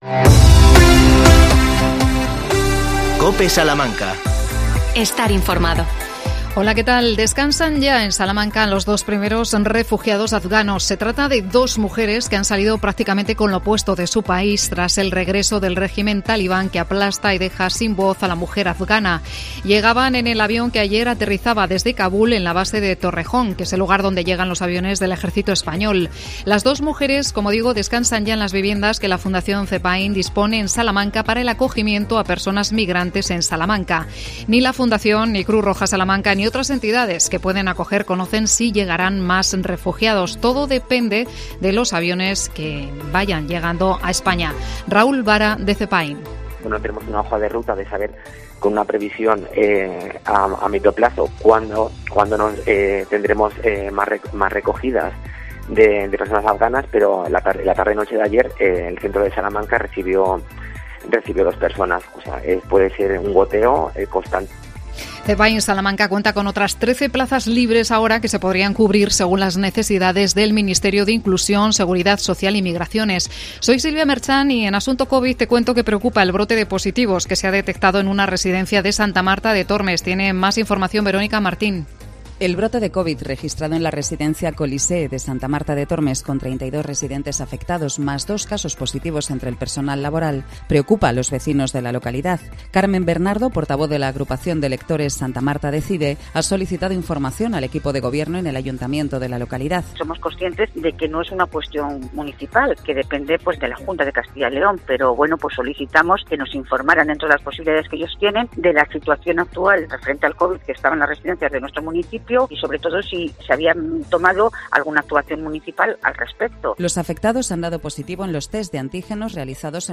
24 08 21 MEDIODIA INFORMATIVO COPE SALAMANCA